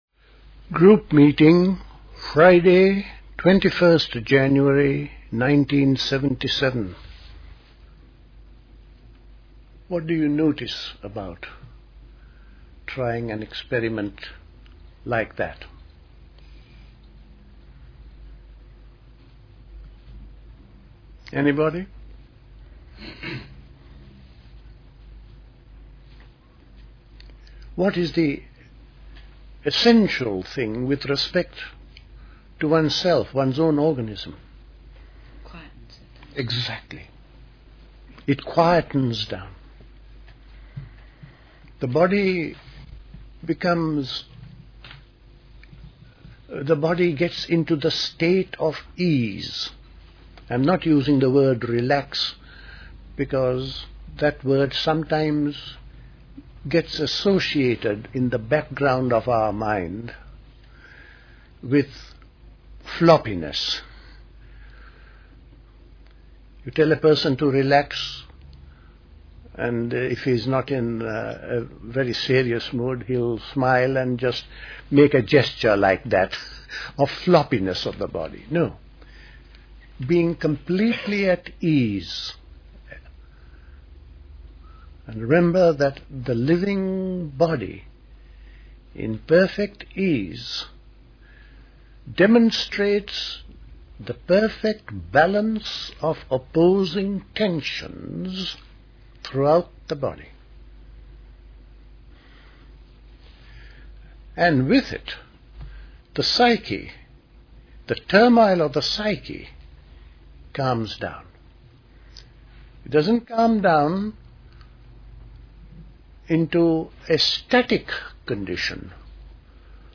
A talk
at Dilkusha, Forest Hill, London on 21st January 1977